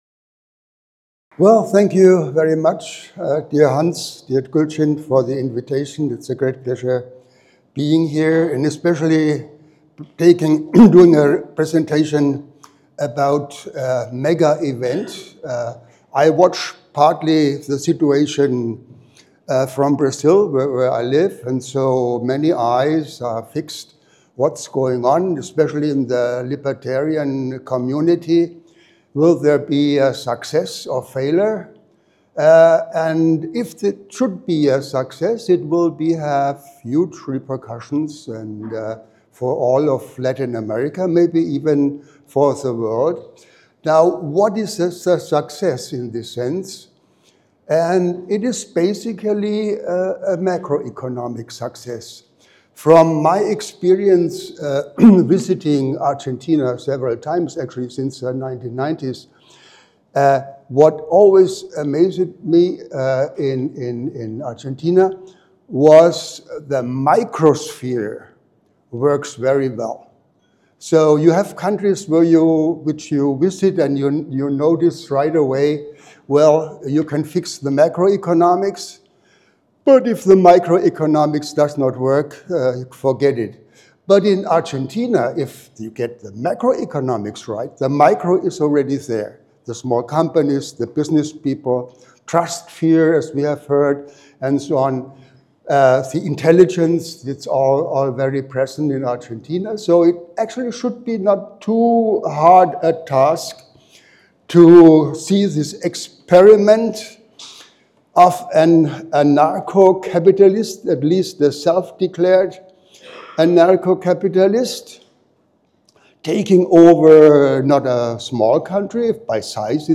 This talk is from the 18th annual 2024 Annual Meeting of the PFS (Sept. 19–24, 2024, Bodrum, Turkey).